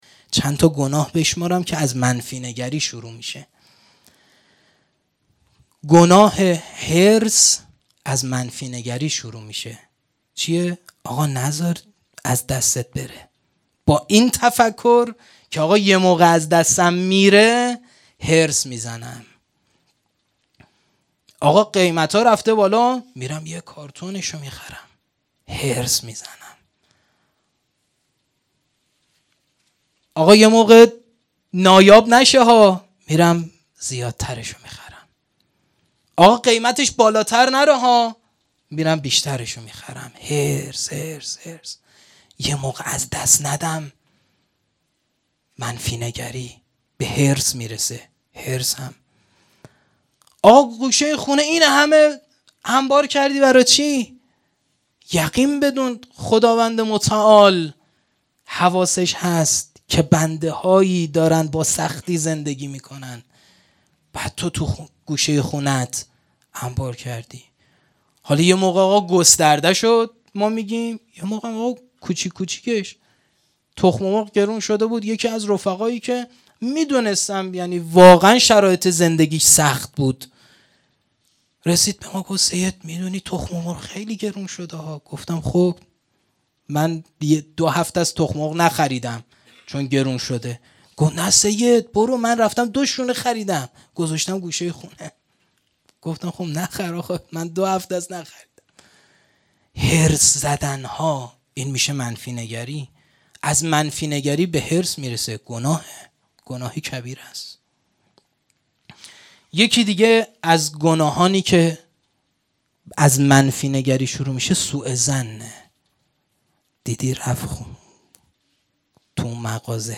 سخنرانی
محرم 1440 _ شب نهم